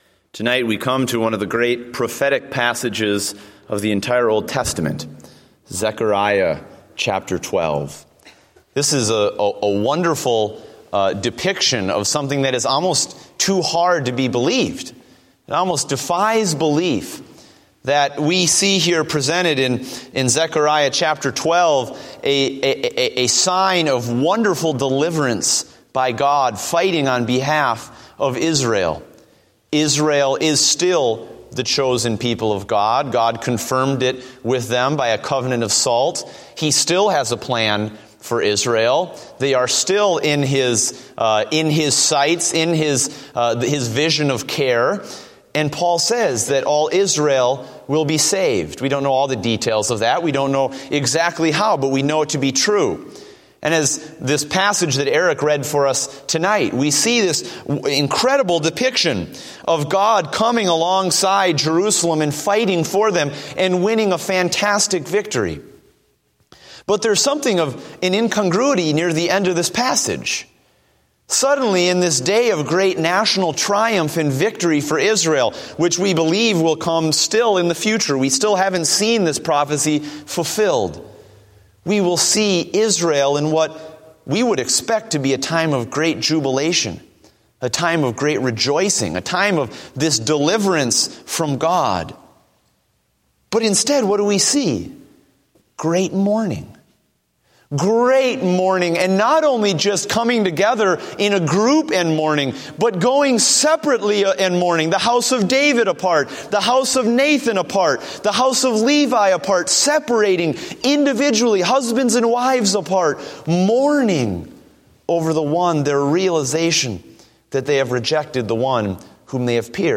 Date: January 4, 2015 (Evening Service)